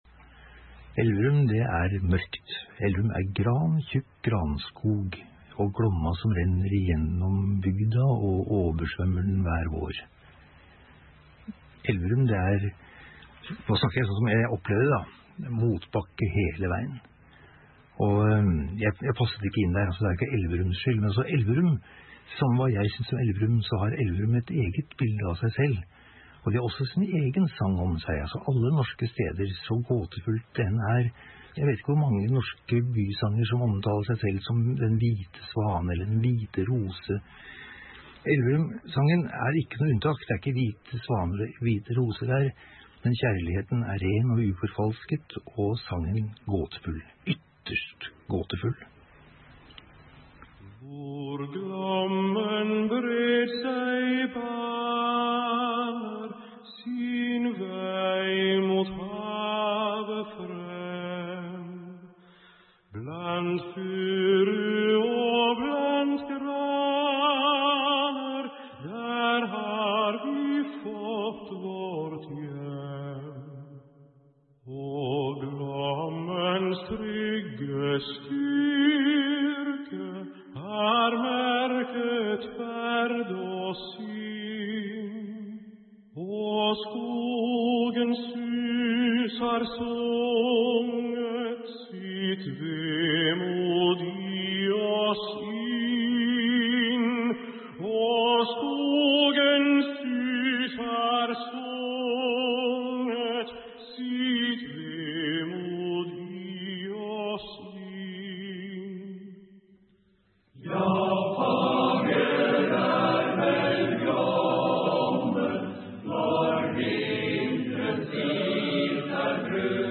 – Elverum Mannskor og Rein Alexander framfører Elverumssangen akkurat slik den skal låte.